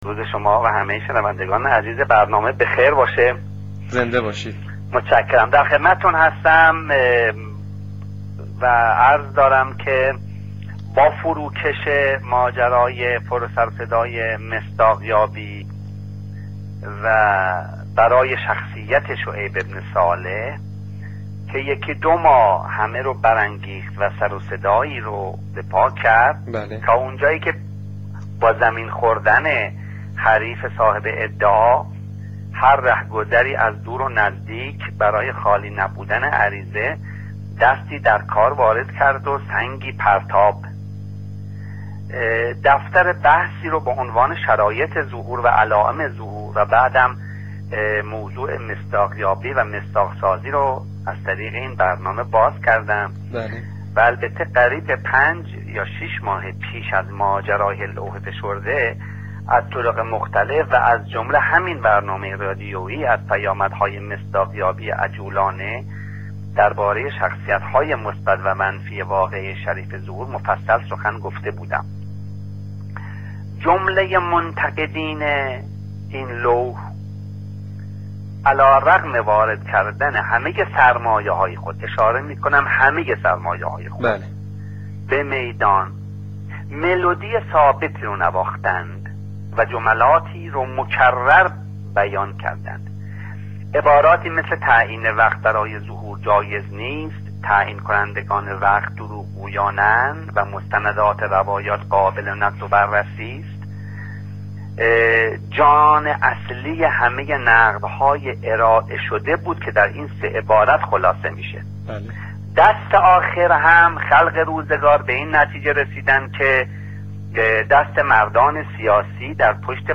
فایل صوتی گفتگوی تلفنی برنامه رادیویی تا جمعه ظهور